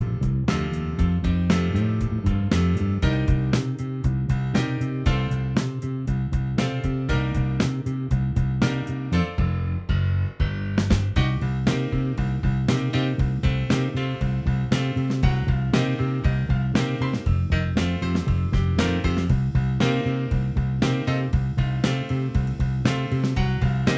Minus All Guitars Pop (1960s) 3:00 Buy £1.50